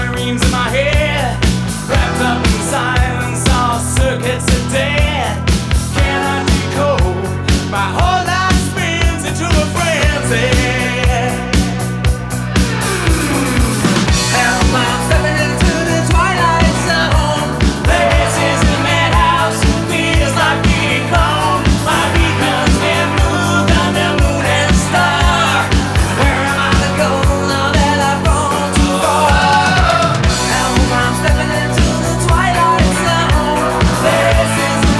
Жанр: Рок
Rock, Hard Rock, Arena Rock